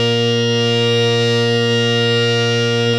52-key05-harm-a#2.wav